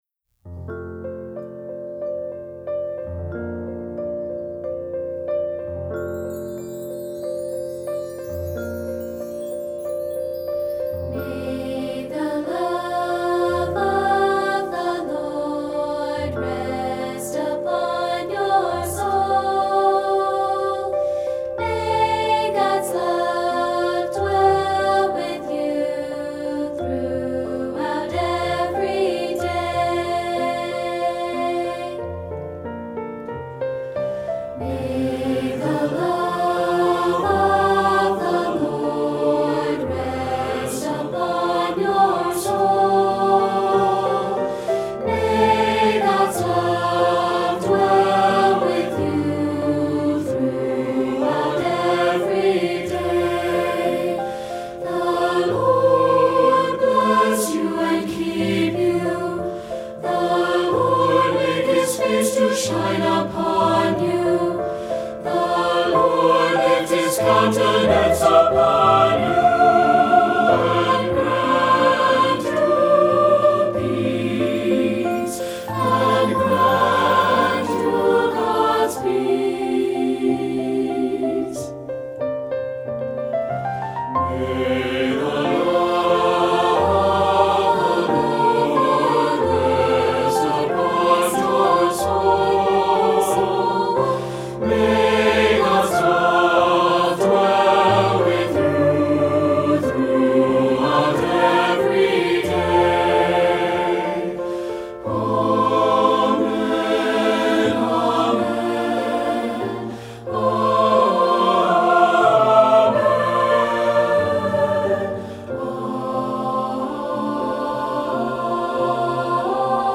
Voicing: U/SATB and Piano